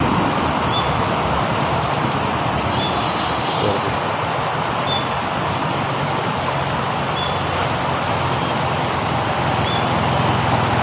large-billed leaf warbler
Phylloscopus magnirostris